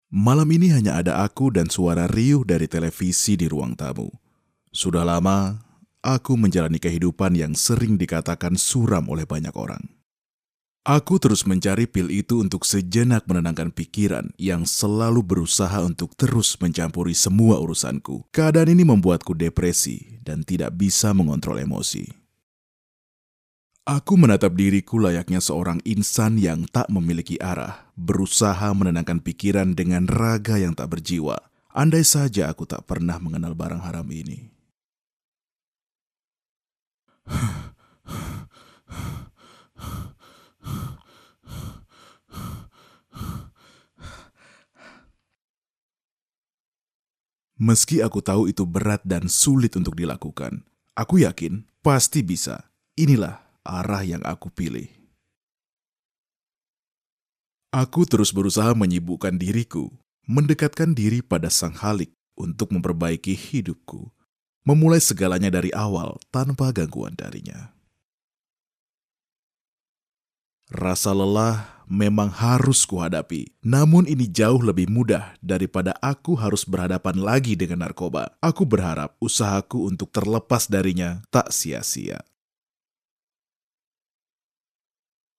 新闻主播【浑厚磁性】